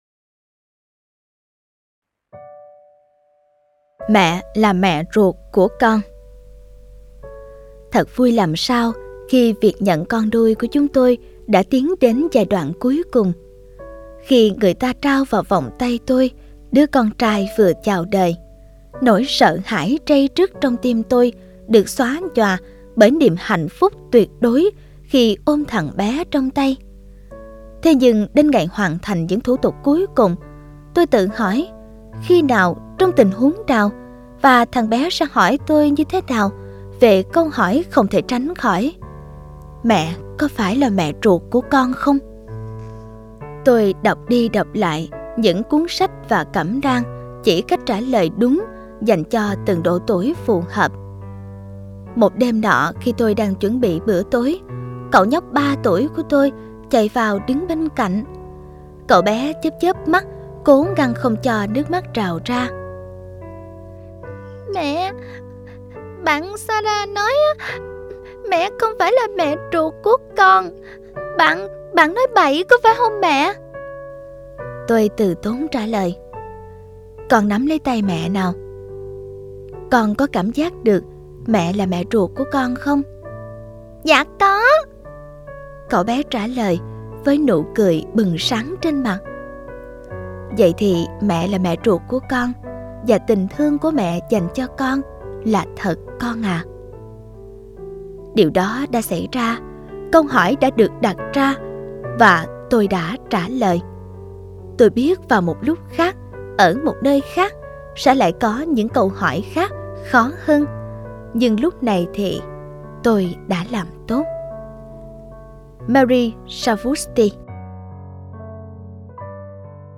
Sách nói Chicken Soup 20 - Hạt Giống Yêu Thương - Jack Canfield - Sách Nói Online Hay